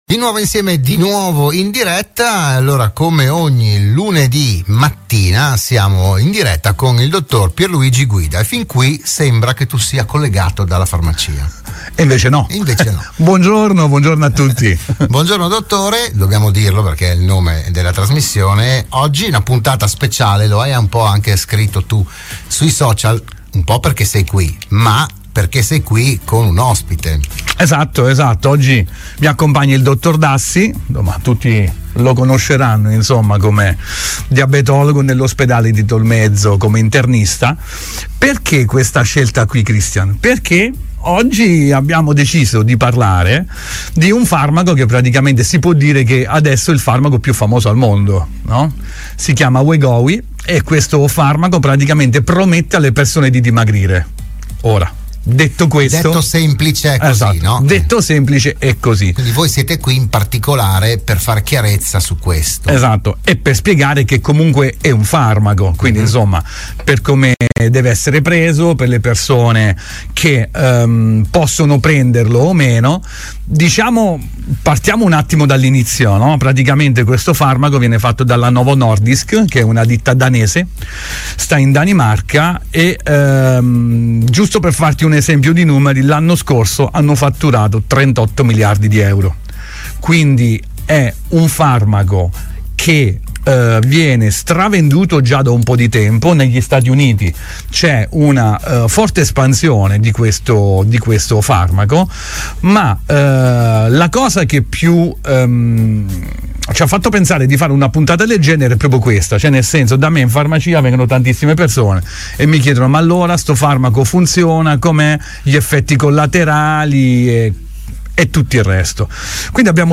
Nuova puntata per “Buongiorno Dottore”, il programma di prevenzione e medicina in onda all’interno della trasmissione di Radio Studio Nord “RadioAttiva”.